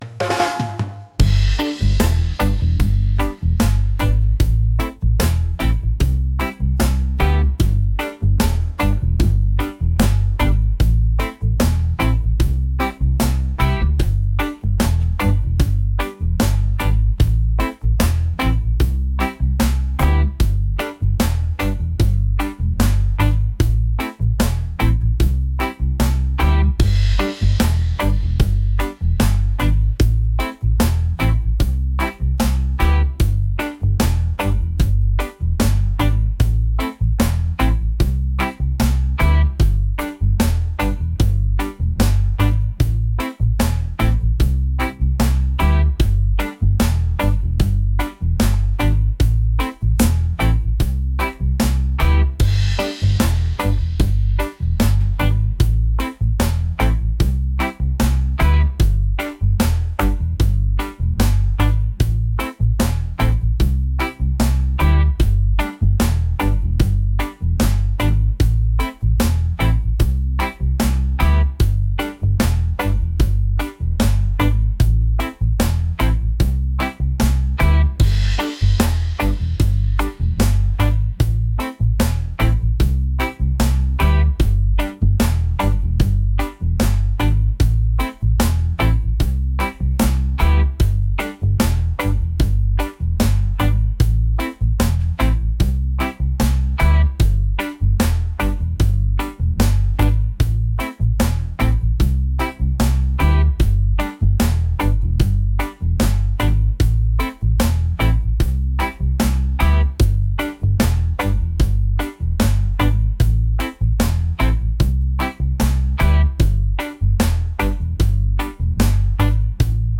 reggae | groovy